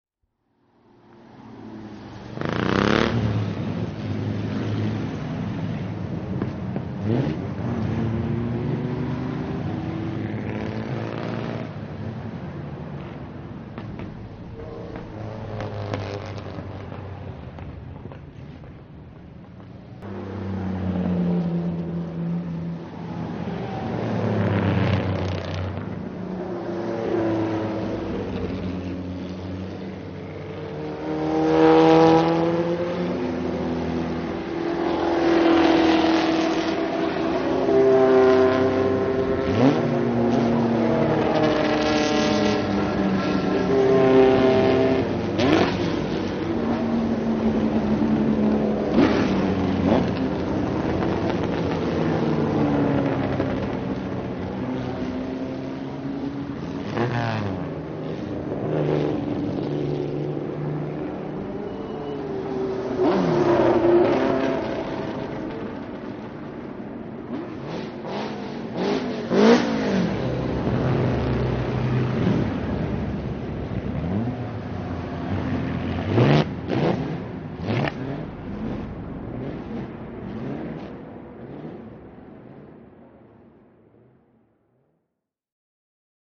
Dröhnende Rennmotoren wie vor 50 Jahren - Solitude Revival 2011 (Veranstaltungen) | Zwischengas
Solitude Revival 2011 - Formel-Rennwagen verschiedener Epochen
Solitude_Revival_2011_-_Formel-Rennwagen_-_verschiedenste_Jahrgaenge_und_Klassen.mp3